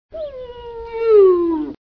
Верблюд жалобно скулит